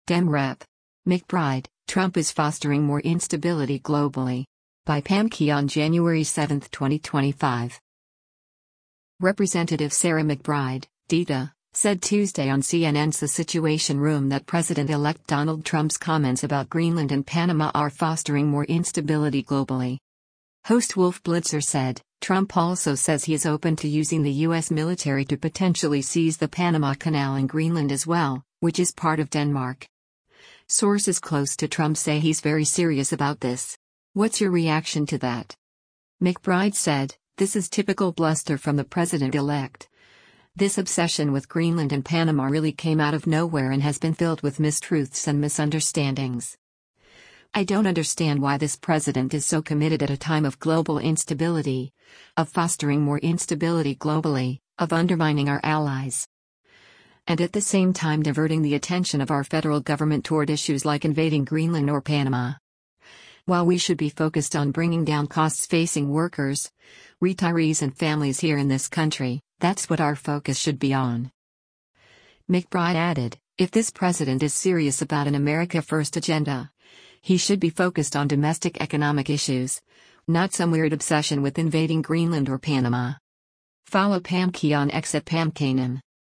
Representative Sarah McBride (D-DE) said Tuesday on CNN’s “The Situation Room” that President-elect Donald Trump’s comments about Greenland and Panama are “fostering more instability globally.”
Host Wolf Blitzer said, “Trump also says he’s open to using the U.S. military to potentially seize the Panama Canal and Greenland as well, which is part of Denmark.